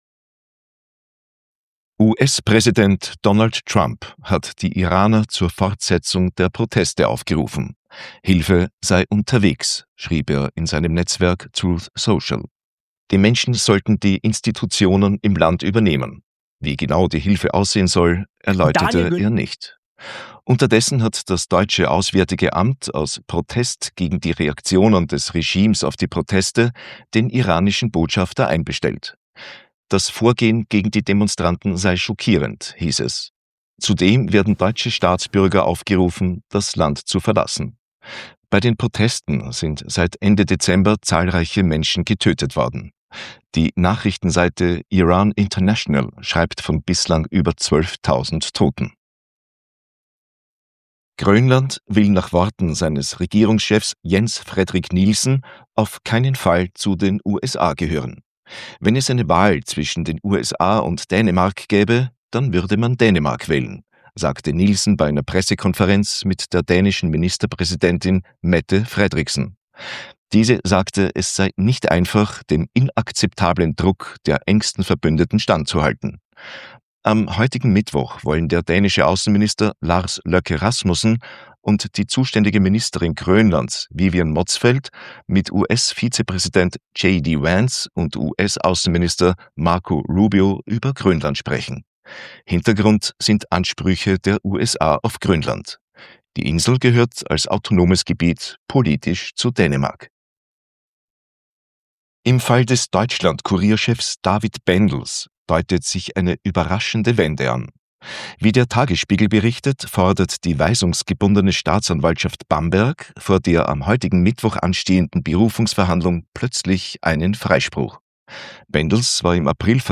Kontrafunk aktuell – Nachrichten vom 14.1.2026